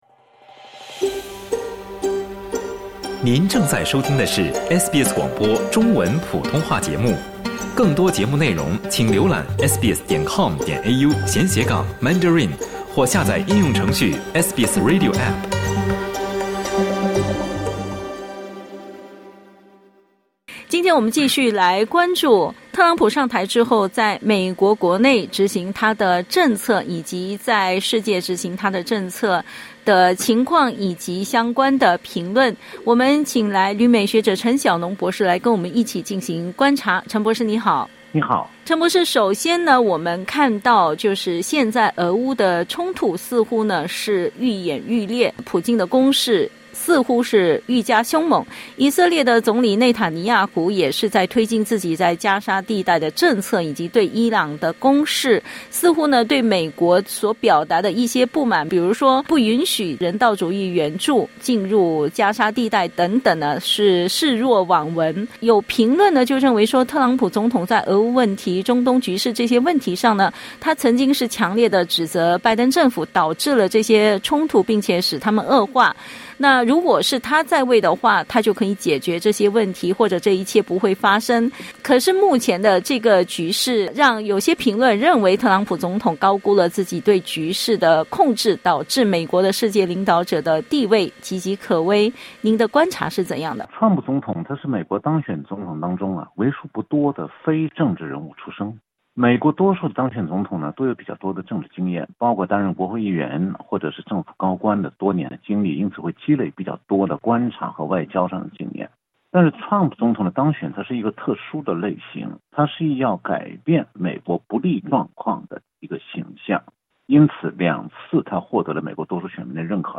（点击音频收听详细采访） 采访内容仅为嘉宾观点 欢迎下载应用程序SBS Audio，订阅Mandarin。